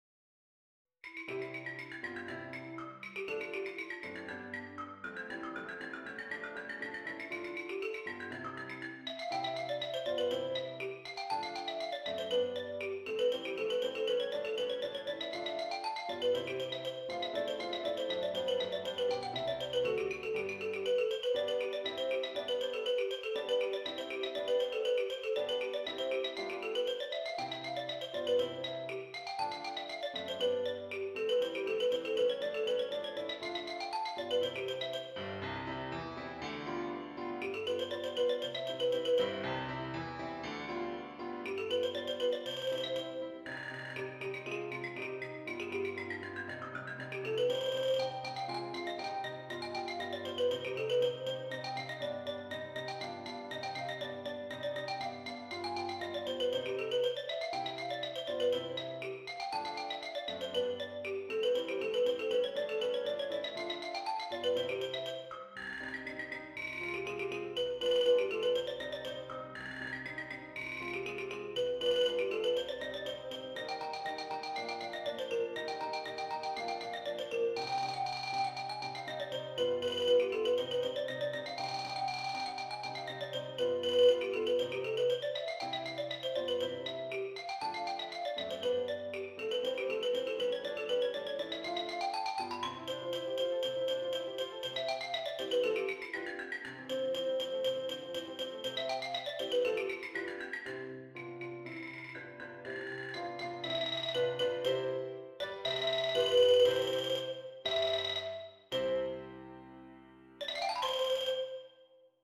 Xylophone and Piano